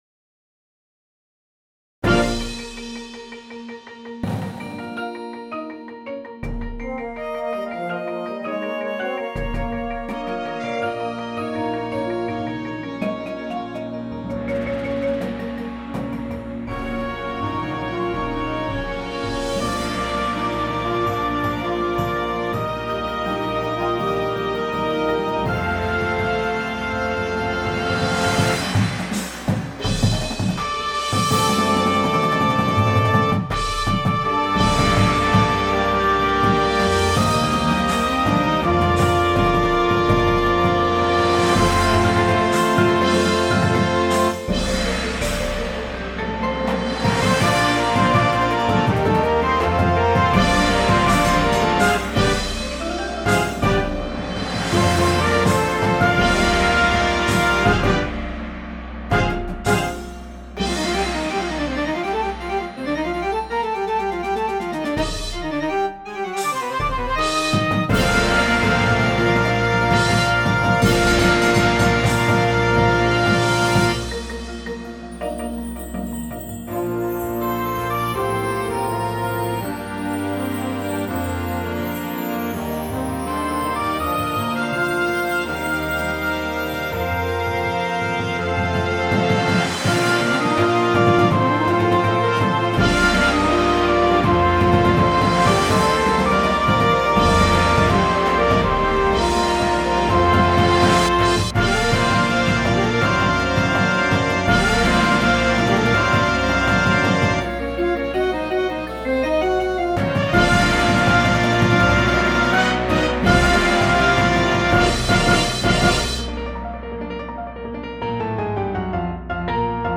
• Flute
• Alto Sax 1, 2
• Trumpet 1
• Horn in F
• Tuba
• Snare Drum
• Bass Drums